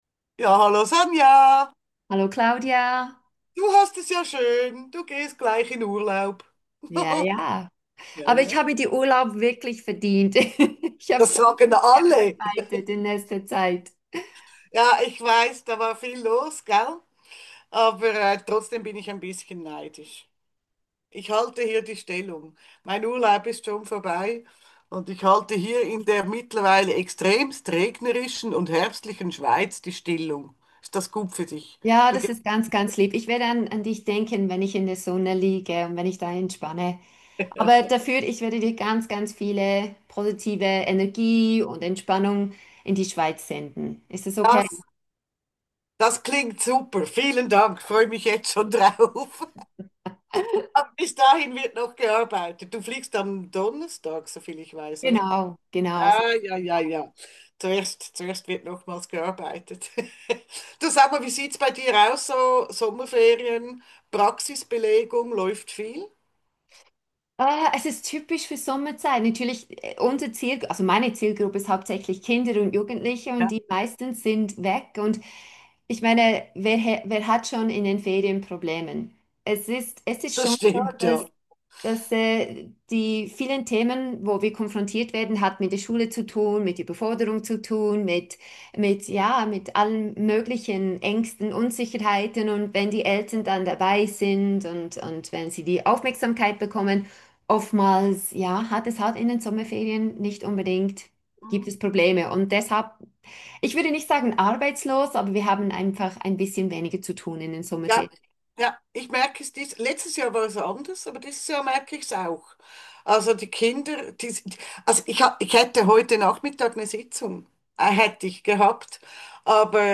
Der spontane Wochentalk